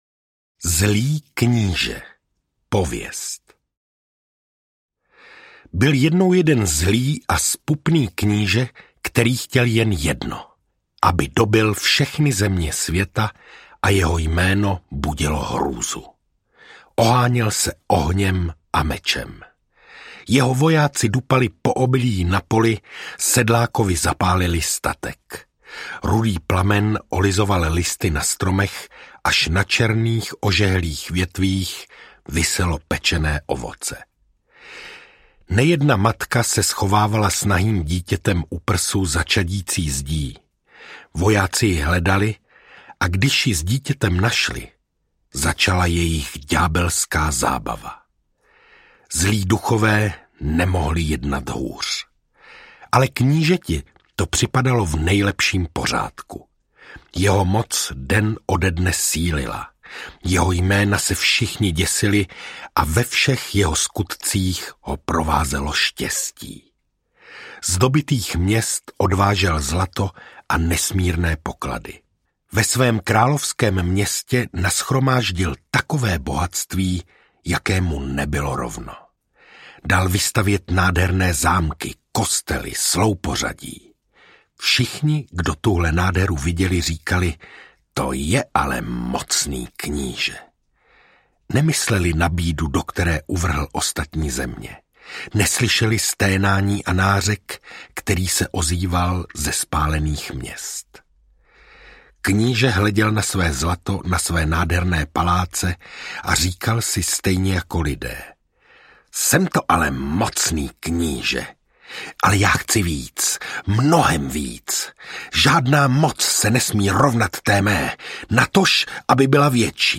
Pohádky a příběhy audiokniha
Ukázka z knihy